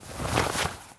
foley_cloth_light_fast_movement_07.wav